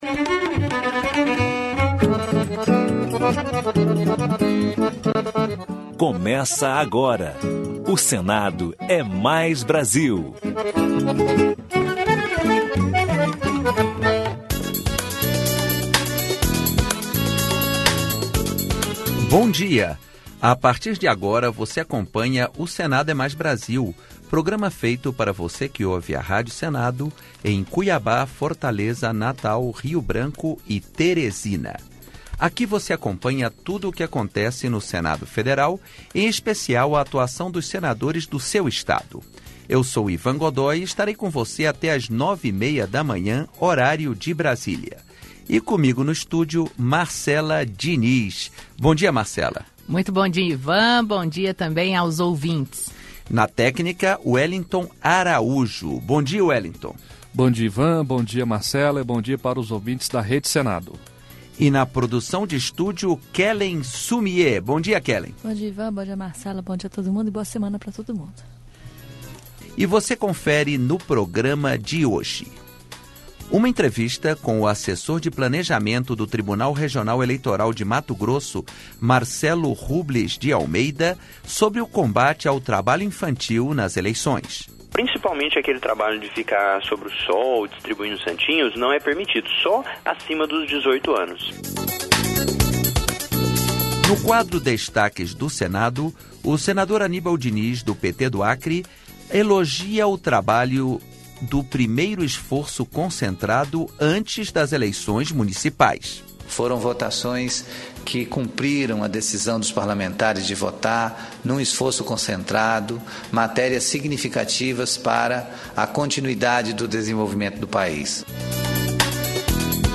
Entrevista Especial